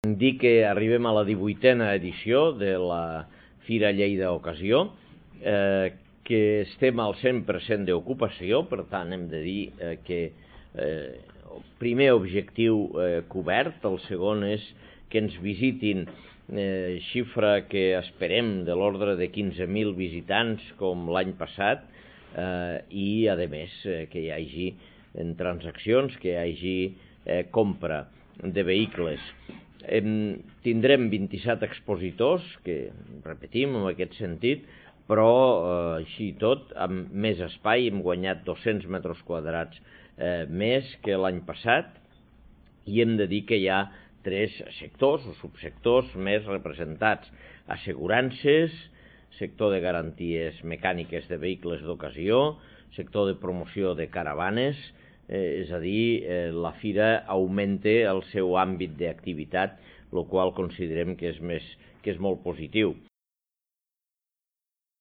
tall-de-veu-de-lalcalde-de-lleida-angel-ros